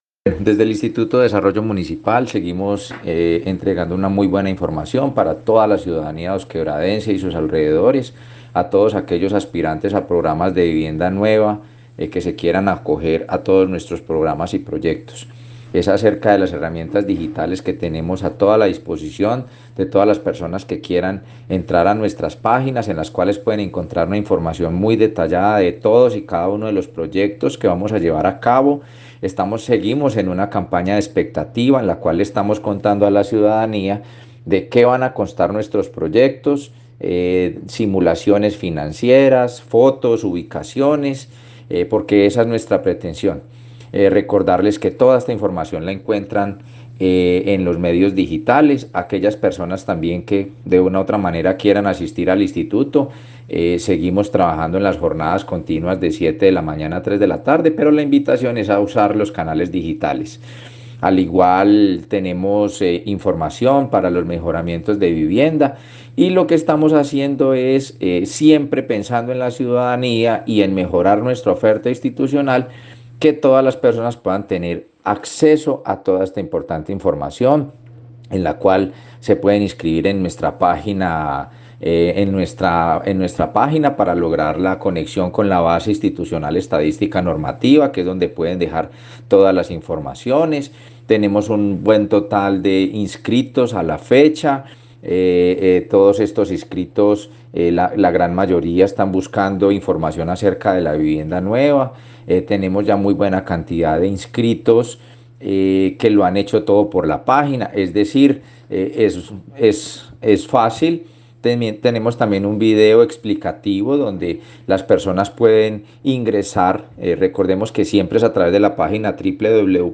Comunicado-260-Audio-Director-del-IDM-Ernesto-Valencia-.mp3